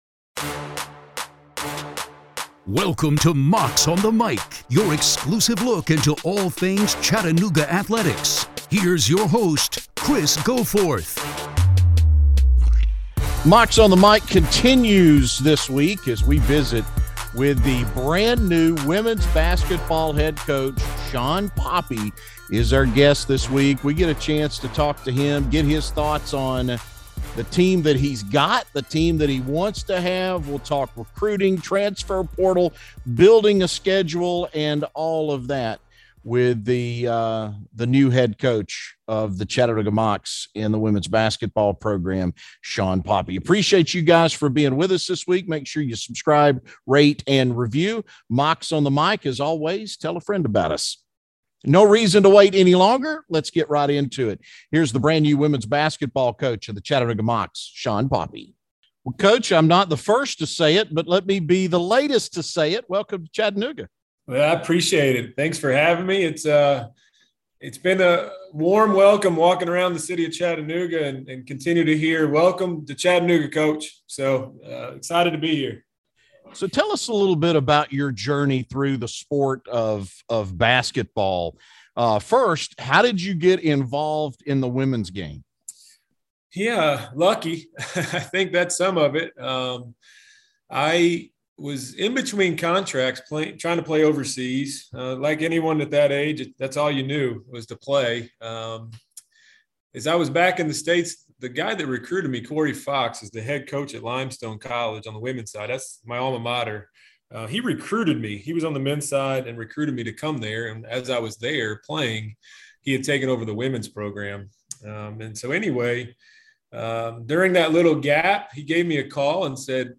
The pair discuss getting settled, recruiting and what's in store for the team in the coming year.